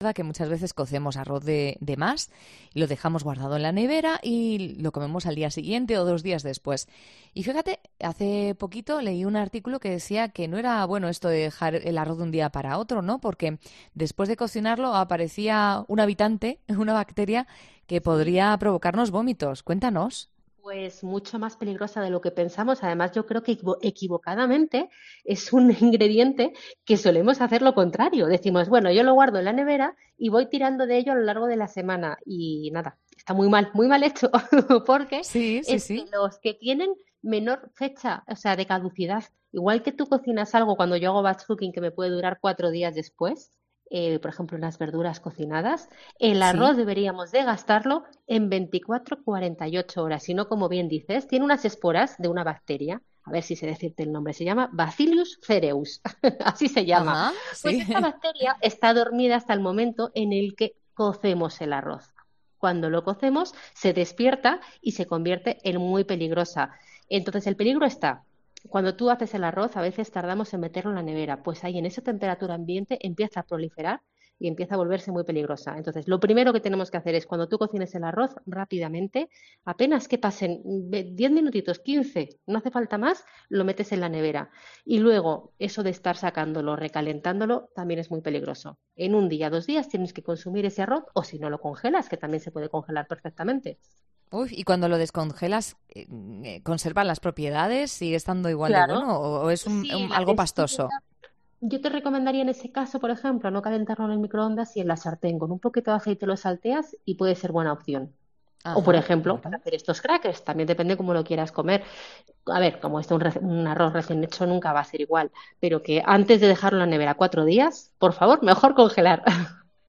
Hace unas semanas en 'La Noche' de COPE poníamos el foco en el tiempo que dura el arroz desde que lo has cocinado.